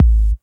CC - Kingdom Kick.wav